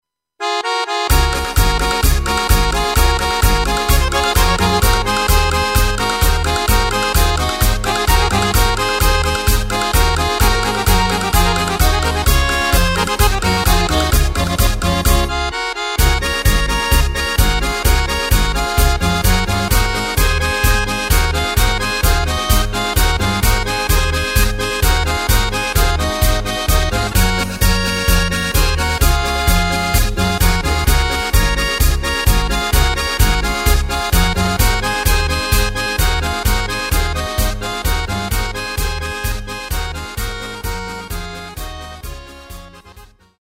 Takt:          2/4
Tempo:         129.00
Tonart:            Db
Playback mp3 Demo